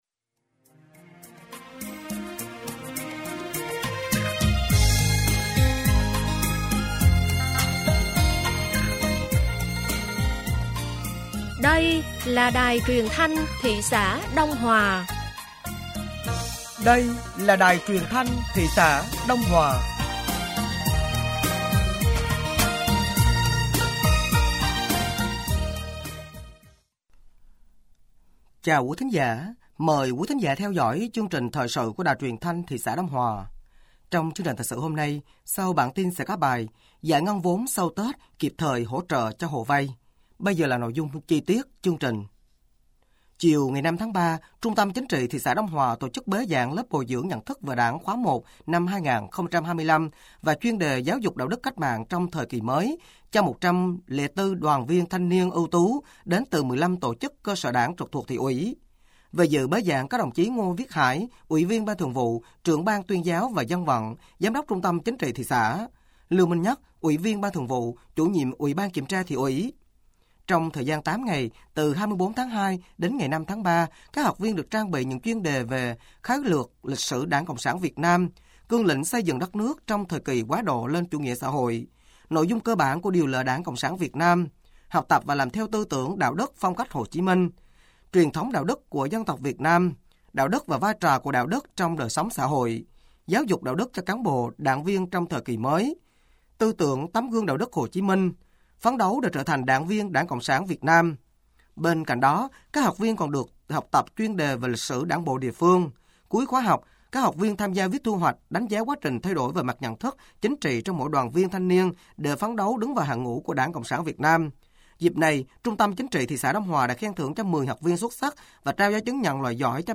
Thời sự tối ngày 06 và sáng ngày 07 tháng 3 năm 2025